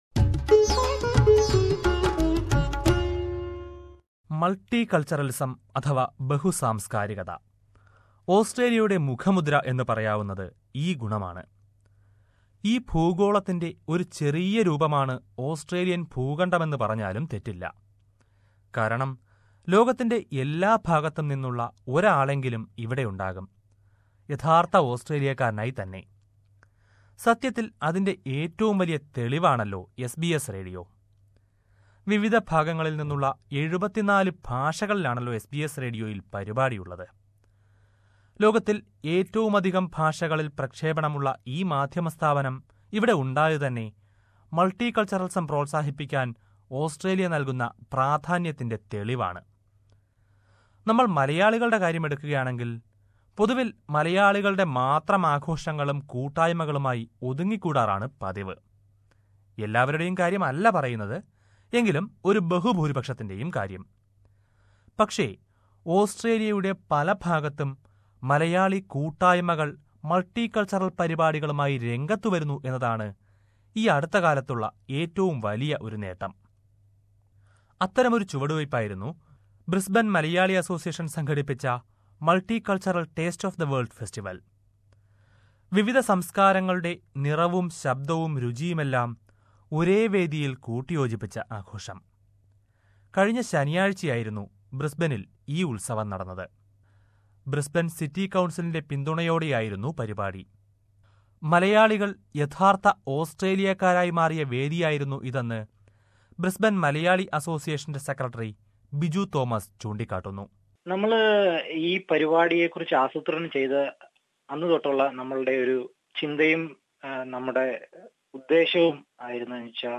But, of late, many Malayalee associations are coming up with multi-cultural events, in tune with the Australan culture. Let us listen to a report on the multi-cultural festival organised by the Brisbane Malayalee Association.